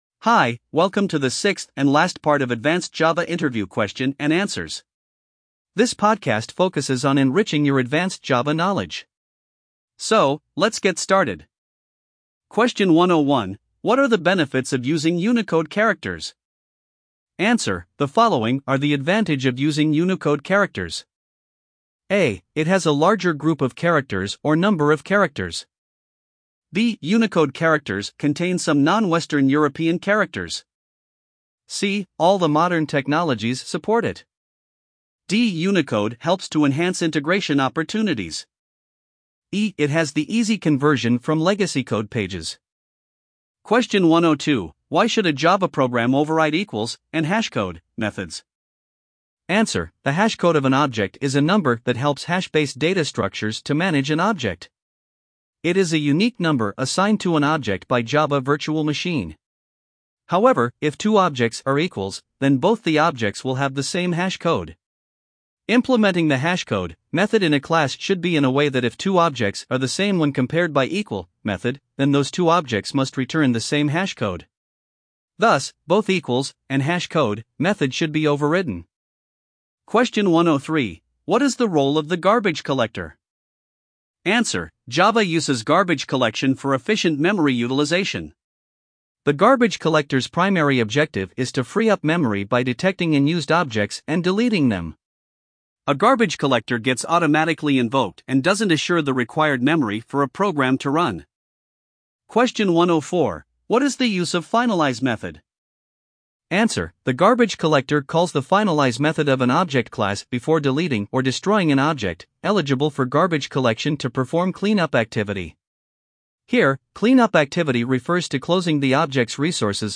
LISTEN TO THE ADVANCED JAVA FAQs LIKE AN AUDIOBOOK